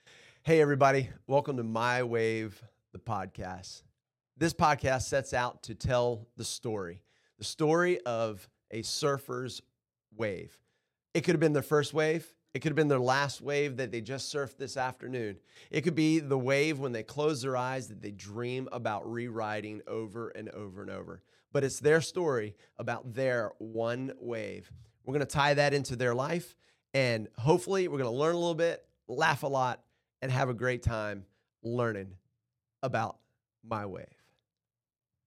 Trailer: My Wave Podcast